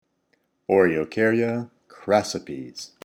Pronunciation/Pronunciación:
O-re-o-cár-ya crás-si-pes